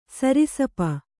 ♪ sarisapa